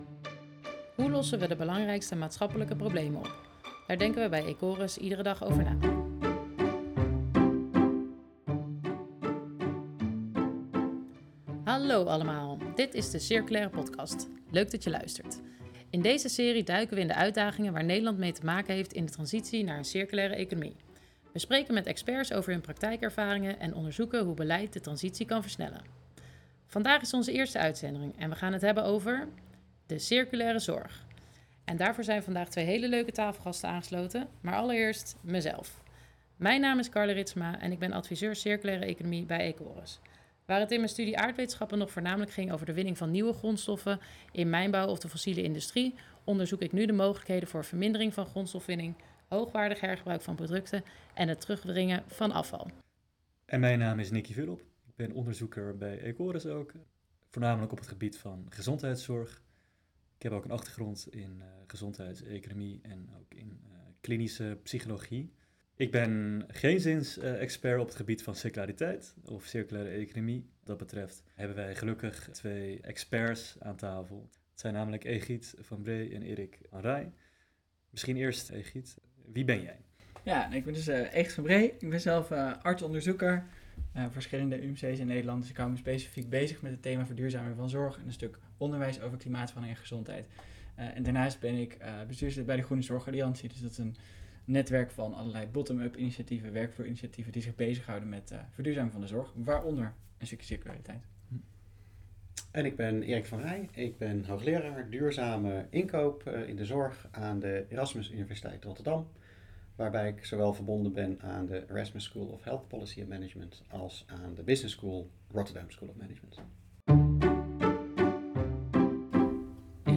In this podcast, they talk to experts about their practical experience, and explore how policy can accelerate the transition.